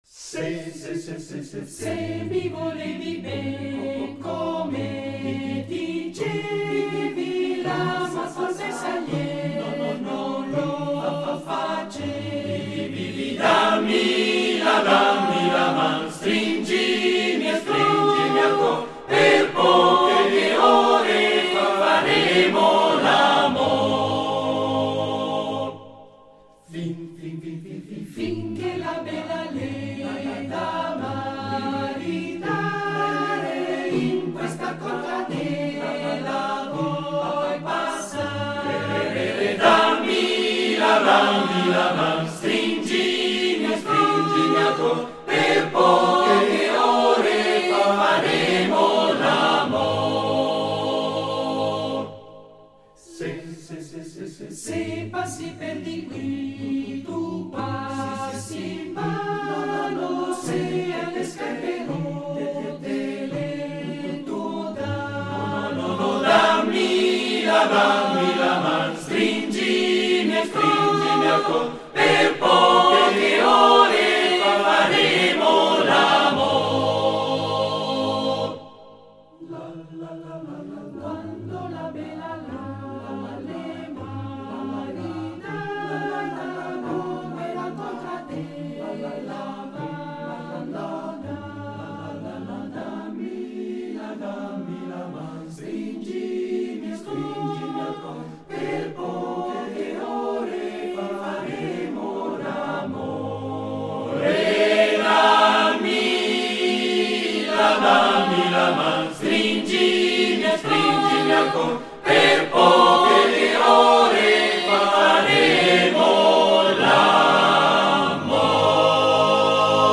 Esecutore: Coro CAI Uget Direttore di coro